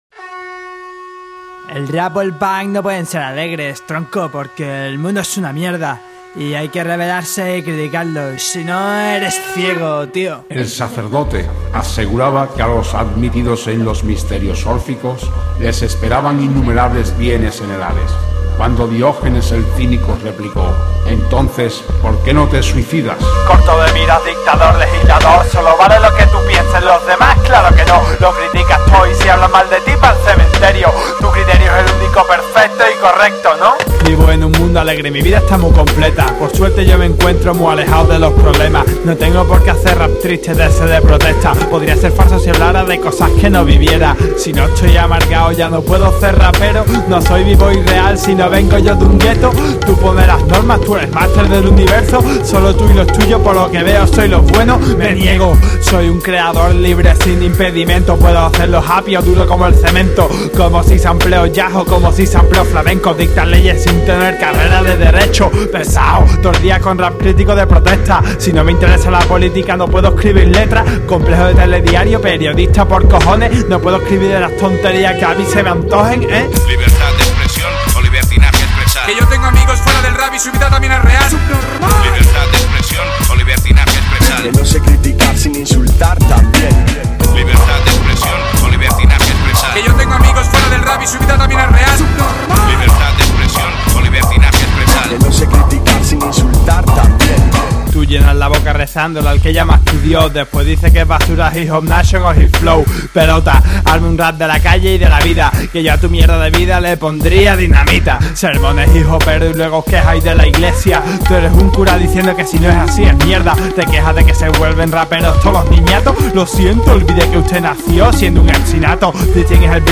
Download bpm 90 rap sobre oskura Channels